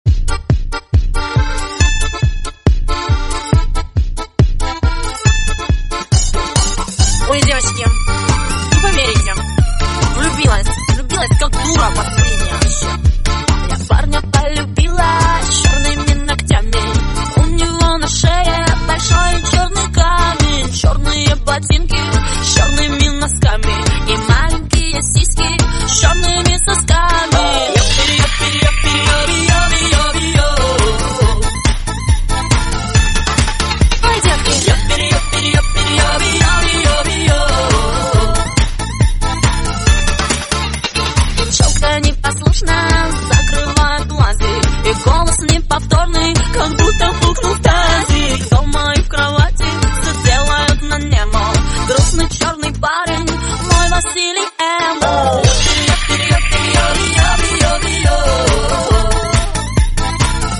• Качество: 64, Stereo
веселые
женский голос
смешные